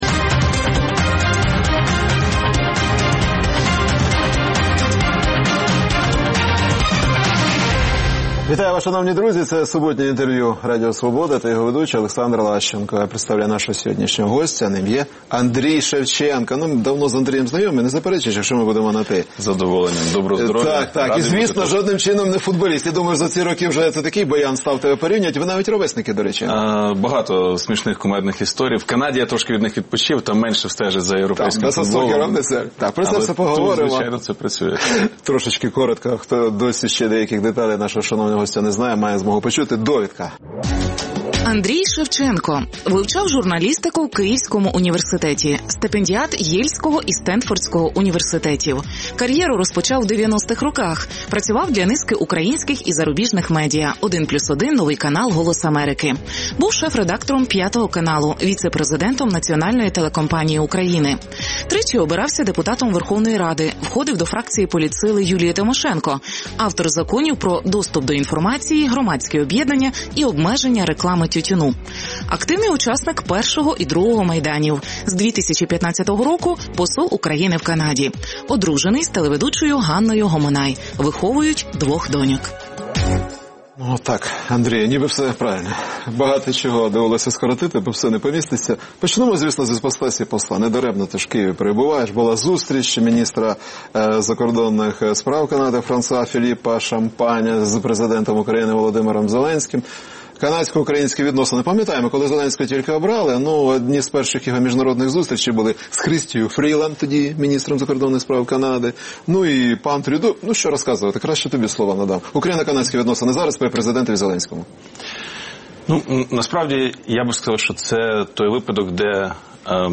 Суботнє інтерв’ю | Андрій Шевченко, посол України в Канаді
Суботнє інтвер’ю - розмова про актуальні проблеми тижня. Гість відповідає, в першу чергу, на запитання друзів Радіо Свобода у Фейсбуці